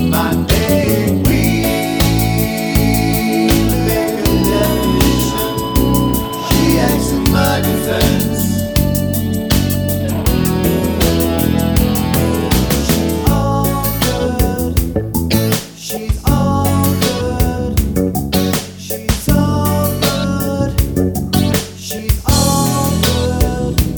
no Backing Vocals Rock 4:22 Buy £1.50